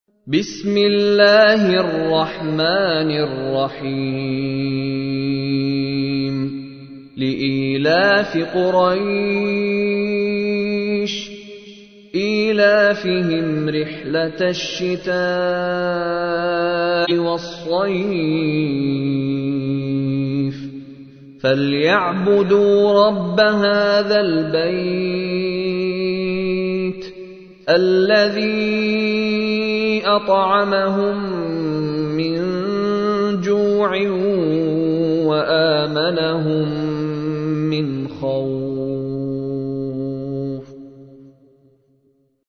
تحميل : 106. سورة قريش / القارئ مشاري راشد العفاسي / القرآن الكريم / موقع يا حسين